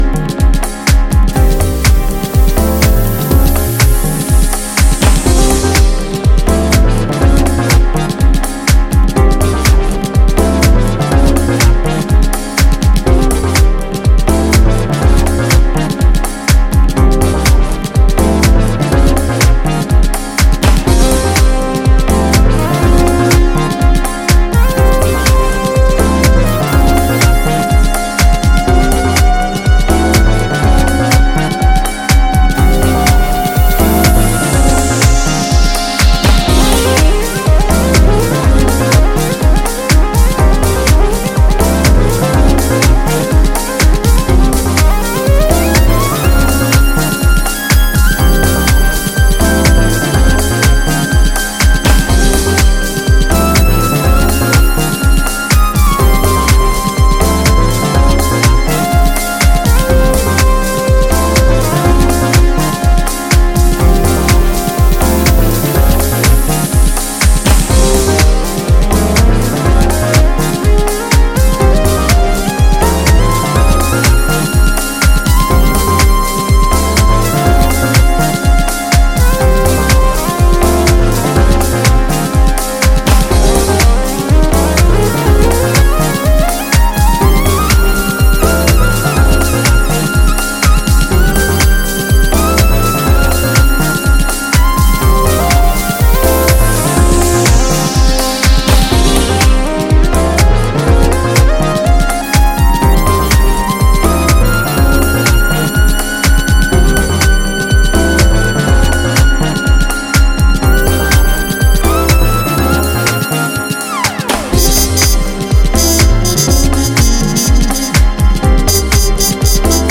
deep house
groove awash in synths and muted trumpet